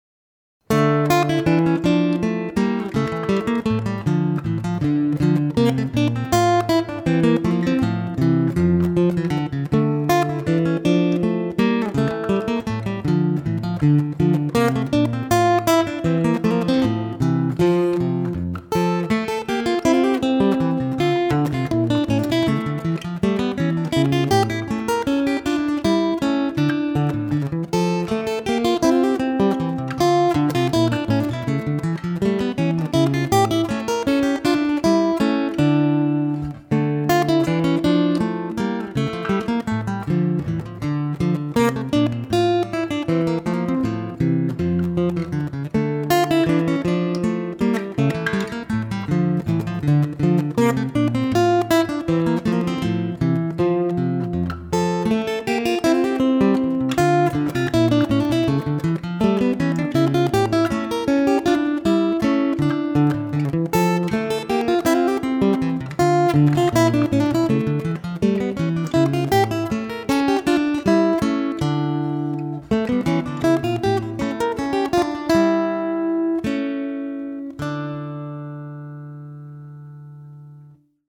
Chitarre   Ascolta brano